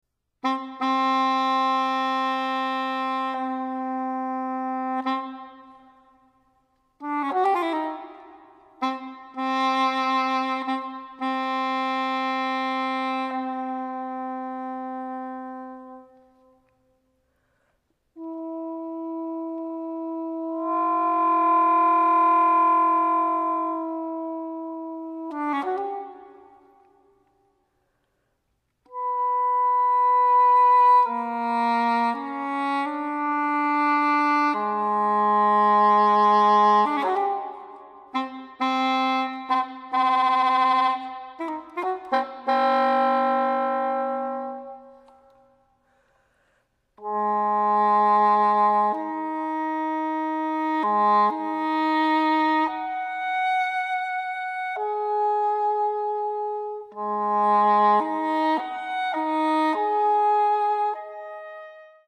For solo English horn.